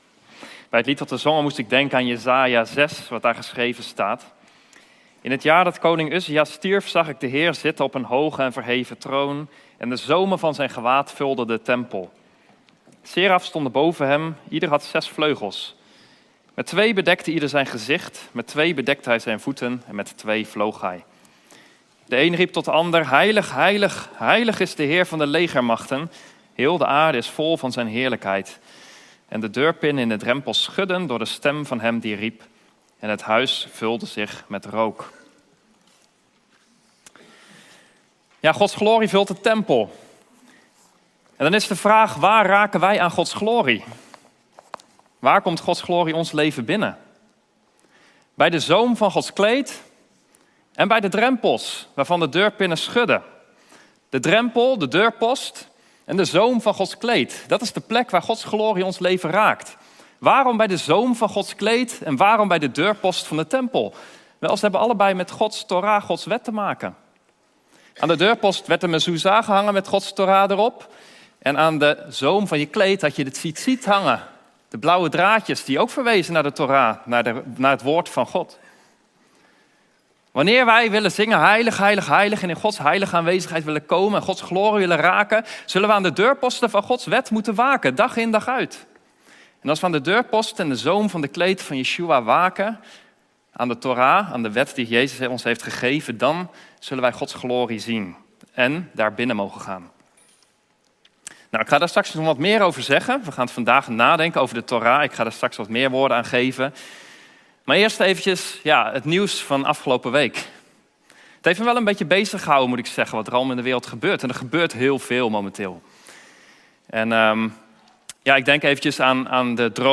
Diversen Soort Dienst: Reguliere dienst « Wat mag het geloof je kosten?